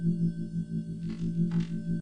Terminal_loop.ogg